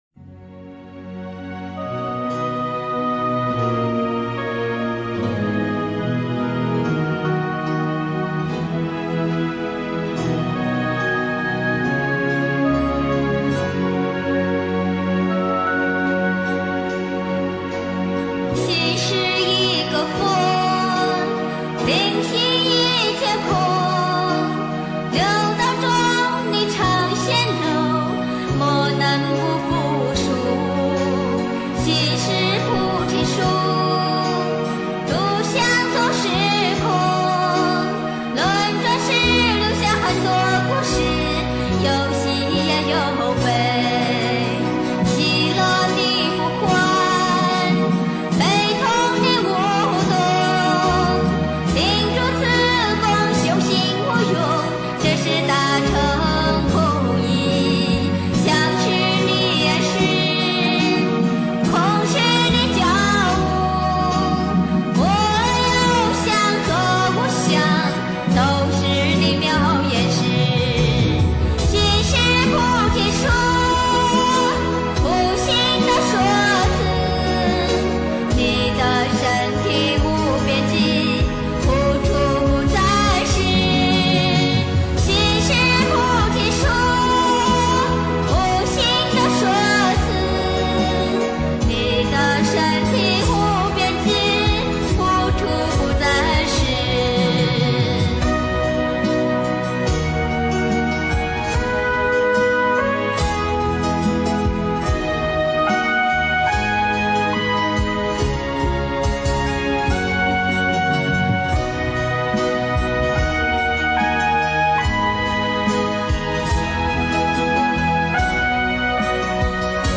心是菩提树 诵经 心是菩提树--佛教音乐 点我： 标签: 佛音 诵经 佛教音乐 返回列表 上一篇： 悟 下一篇： 修的更高 相关文章 献给菩萨--大宝法王 献给菩萨--大宝法王...